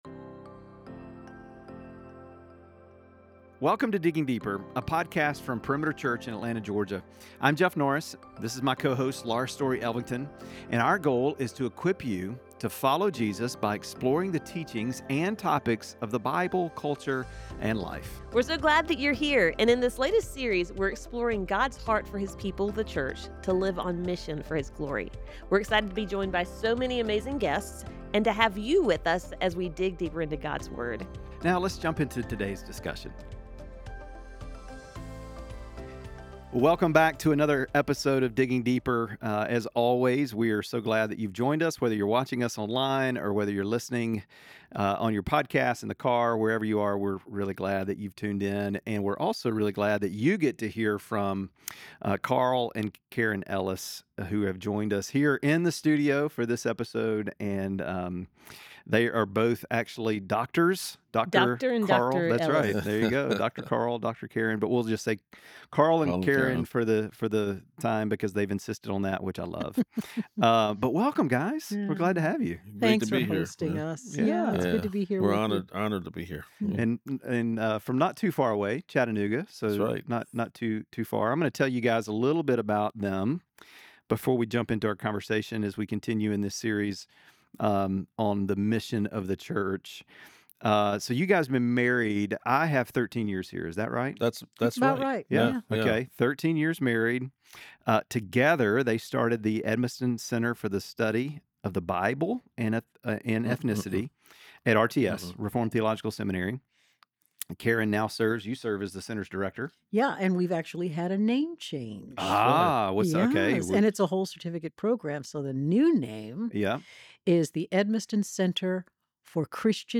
Listen as they unpack what it means to live with purpose, walk in faith, and engage in the work God has called us to. We hope this conversation will encourage you to step boldly into God's calling.